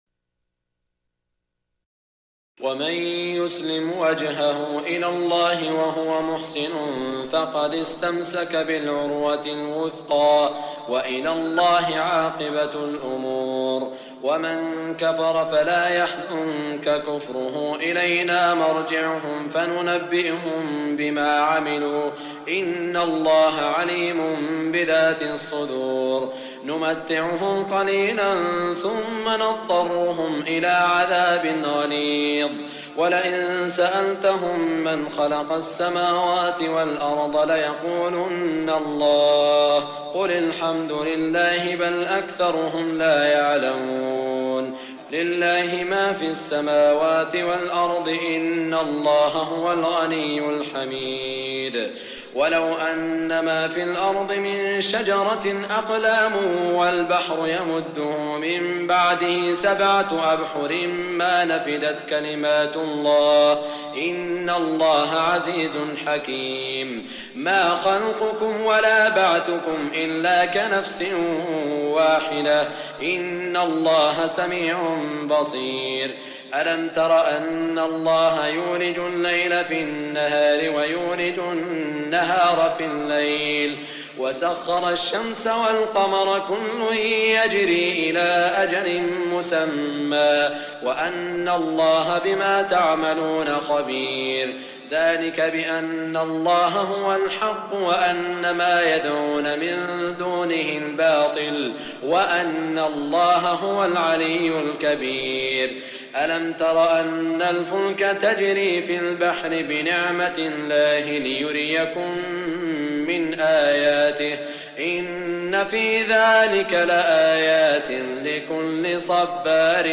سورة لقمان (٢٢ - آخرها) من عام ١٤١٠ | بمدينة الرياض > الشيخ سعود الشريم تلاوات ليست من الحرم > تلاوات وجهود أئمة الحرم المكي خارج الحرم > المزيد - تلاوات الحرمين